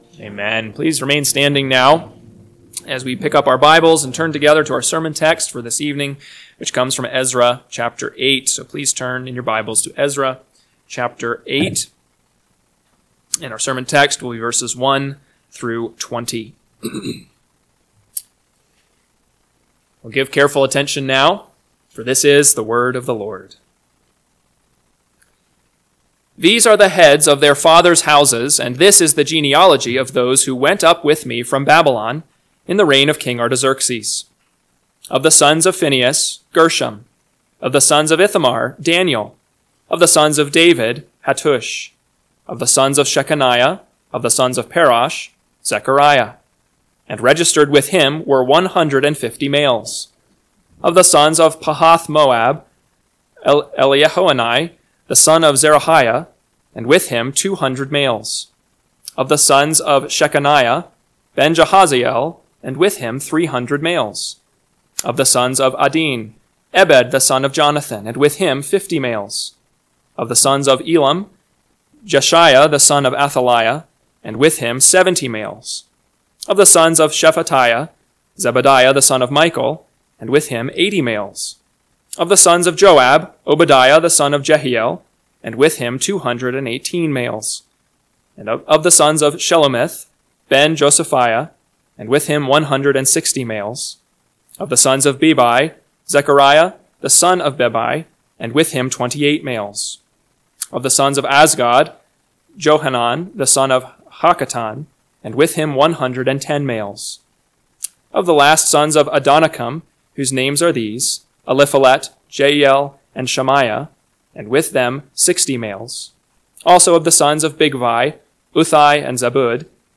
PM Sermon – 5/4/2025 – Ezra 8:1-20 – Northwoods Sermons